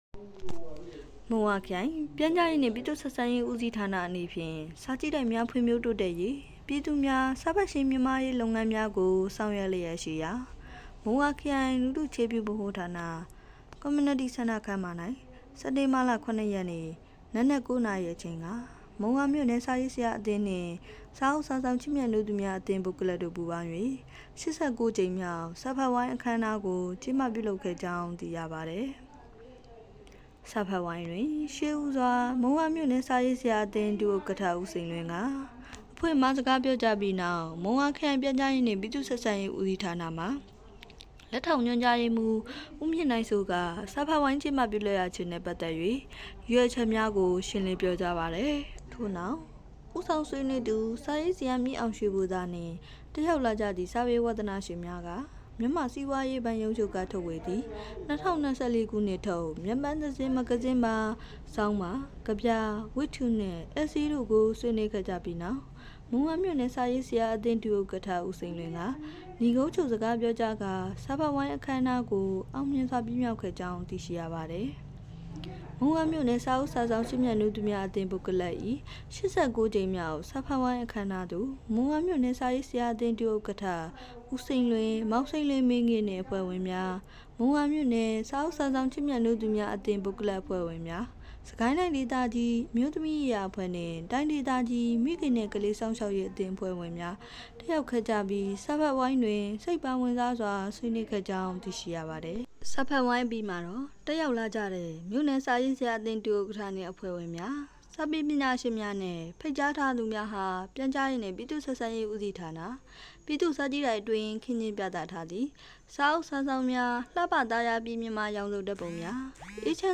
မုံရွာခရိုင် လူထုအခြေပြုဗဟိုဌာန (Community Centre ) ခန်းမ၌ မုံရွာမြို့နယ် စာအုပ်စာစောင် ချစ်မြတ်နိုးသူများအသင်း(Book Club)၏ (၈၉)ကြိမ်မြောက် စာဖတ်ဝိုင်းကျင်းပ မုံရွာ စက်တင်ဘာ ၉